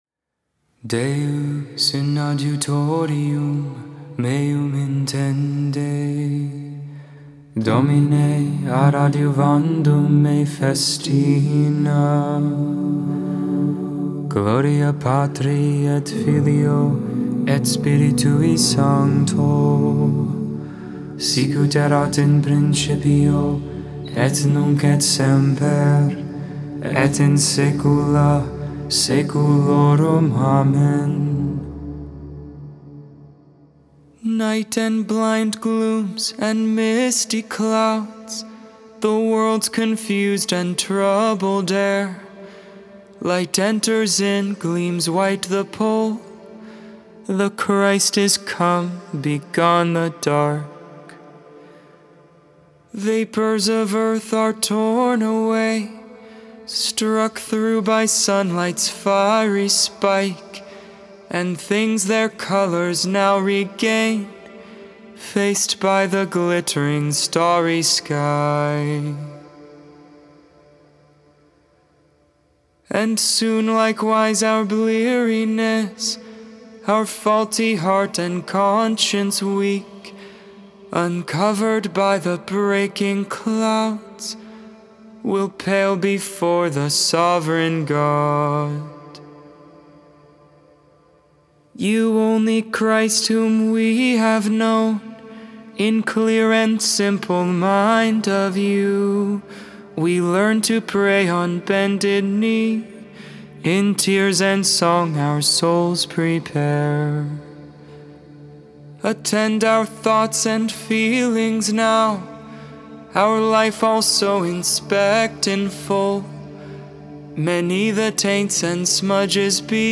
Gregorian